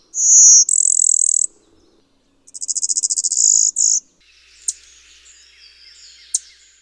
Blue-winged Warbler
Reinita Aliazul
Vermivora pinus